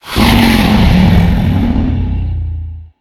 Minecraft Version Minecraft Version snapshot Latest Release | Latest Snapshot snapshot / assets / minecraft / sounds / mob / enderdragon / growl4.ogg Compare With Compare With Latest Release | Latest Snapshot
growl4.ogg